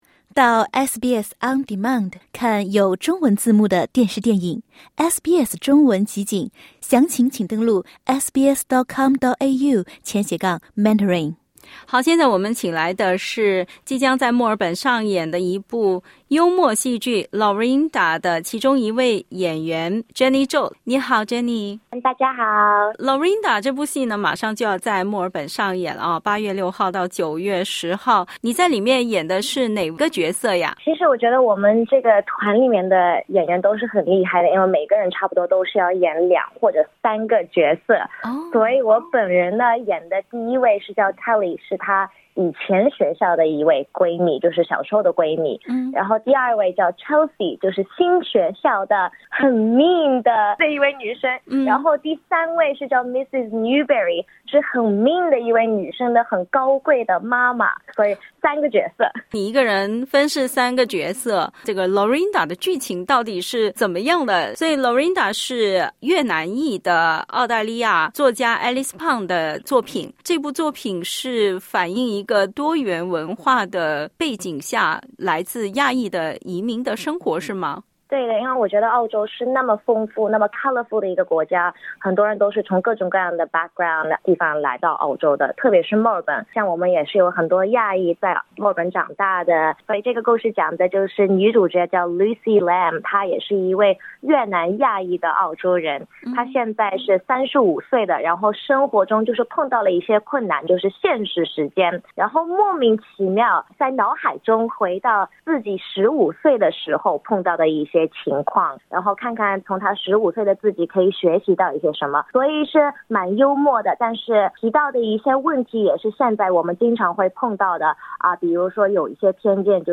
（點擊圖片收聽完整寀訪）